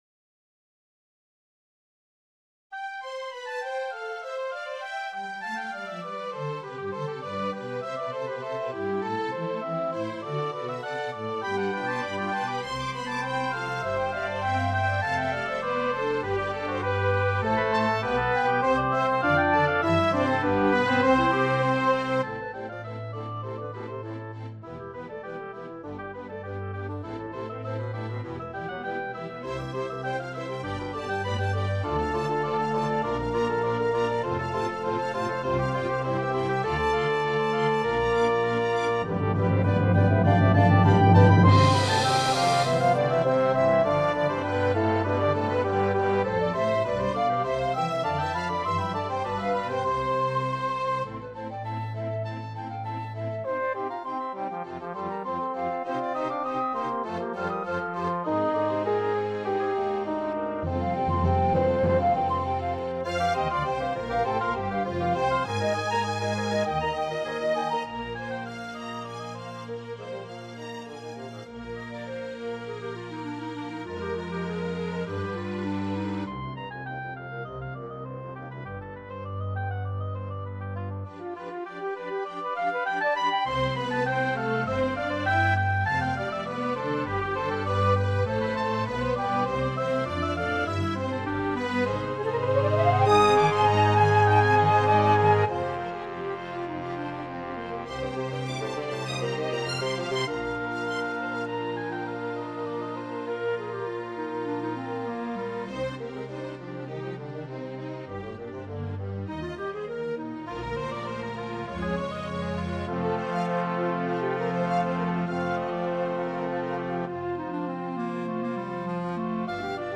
Playback courtesy of Sibelius Sound Essentials.
My first attempt at an orchestral work.